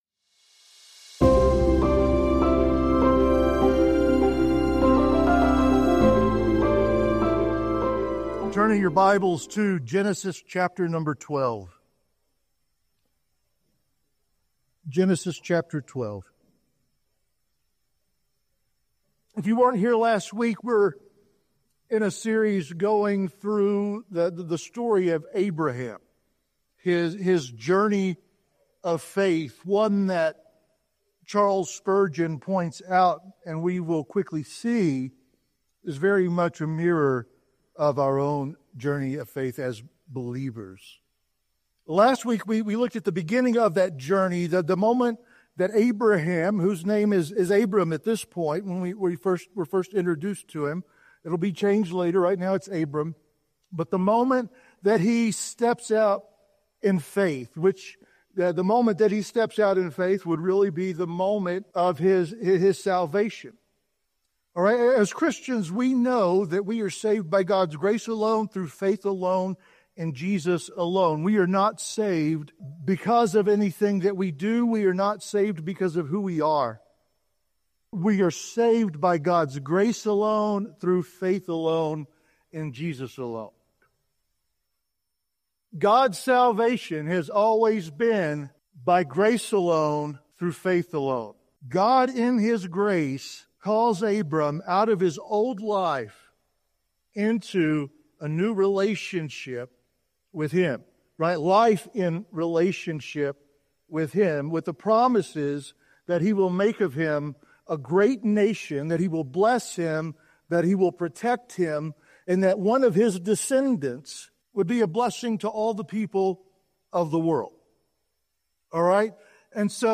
Sermons | Big Horn Baptist Church